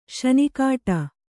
♪ śani kāṭa